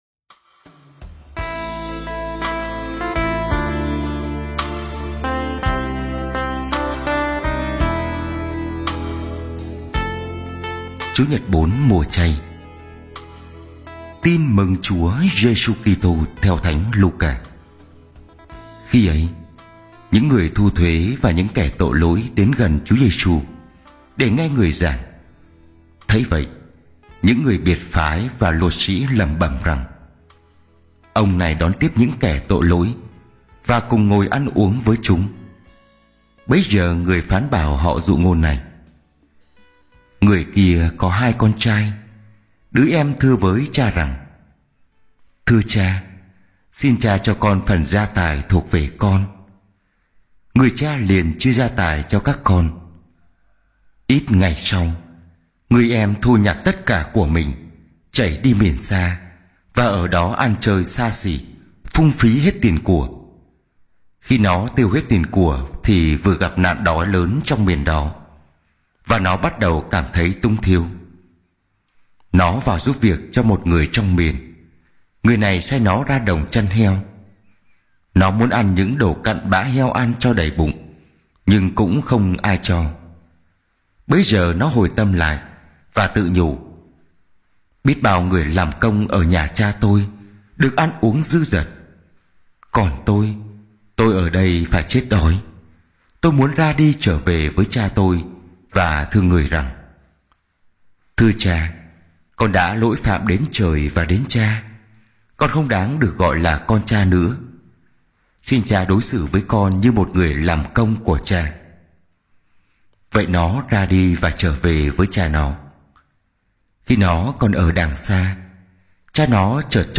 Bài giảng lễ Chúa nhật 4 mùa chay C - 2025